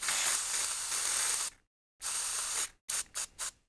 HAIRSPRY 1-S.WAV